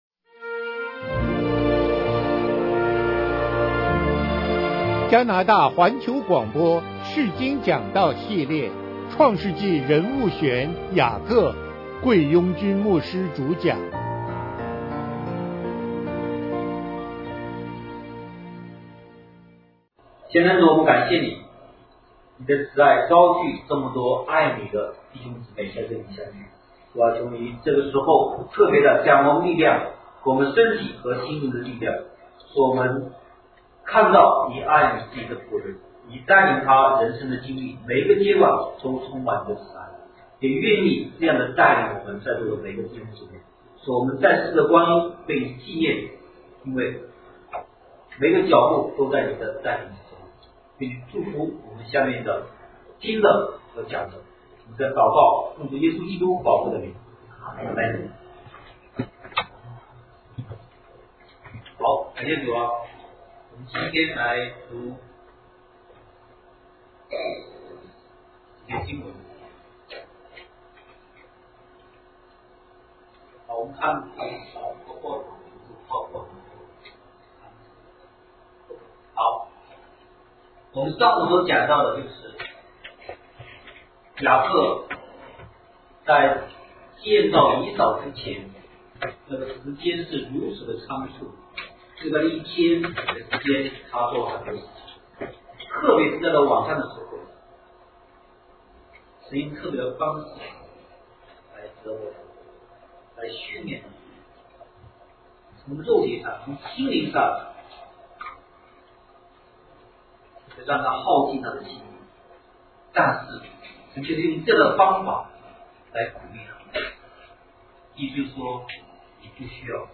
講座錄音 創世記人物選 1 創世記人物選 2 講義 講義一 (人生路上的伯特利) 講義二 (人生路上的毘努伊勒) 講義三 (人生路上的希伯崙) 釋經講道系列 – 創世記人物選